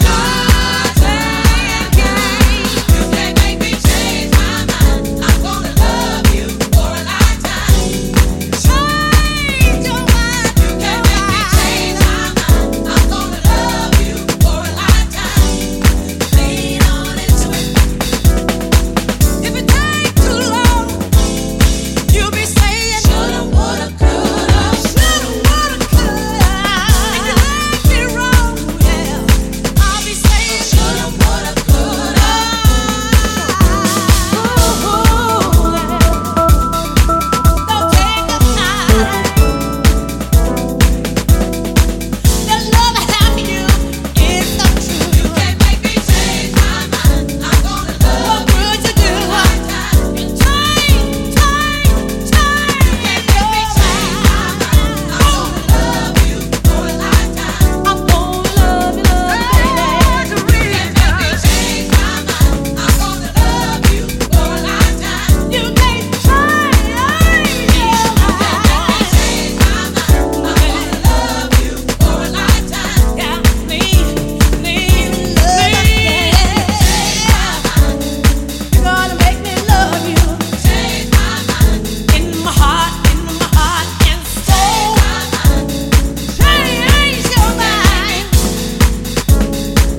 原曲のソウル/ゴスペル感を存分に活かしたエモーショナルなヴォーカル・ハウスに仕上がっています。
ジャンル(スタイル) DEEP HOUSE / SOULFUL HOUSE / HOUSE